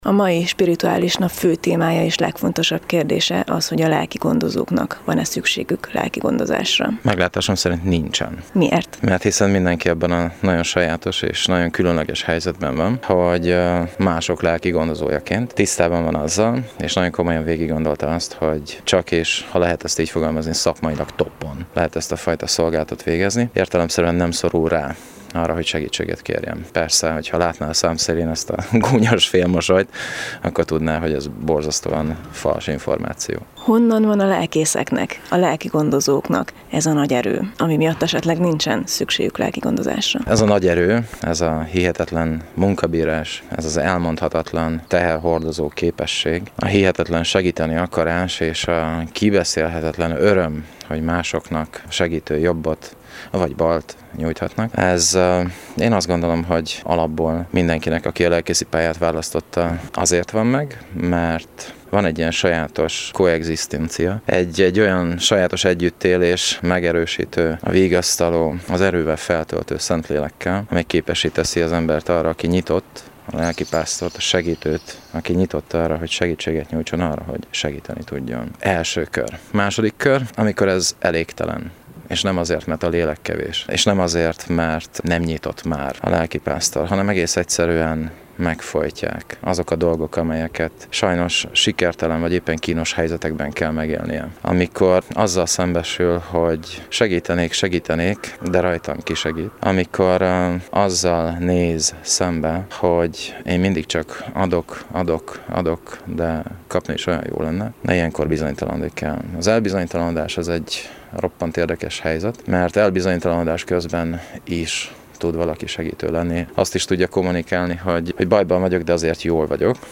Az Európa Rádió helyszínen készített riportját itt meghallgathatja.